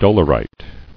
[dol·er·ite]